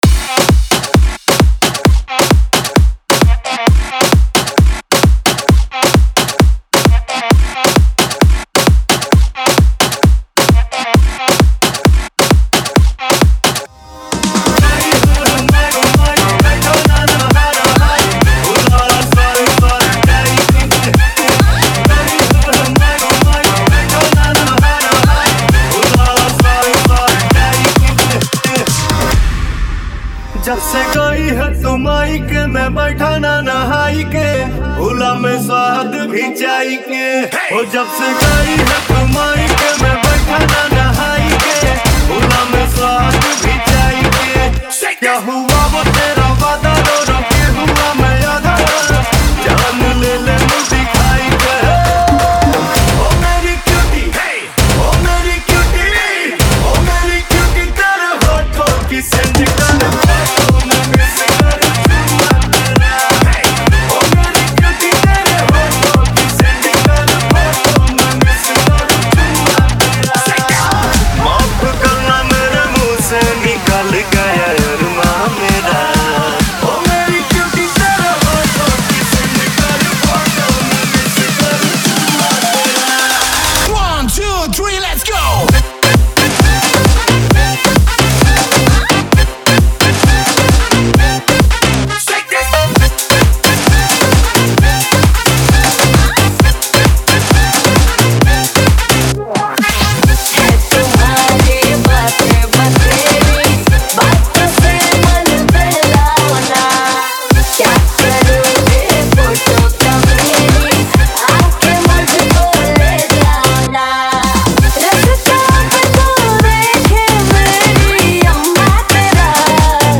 2024 Bollywood Single Remixes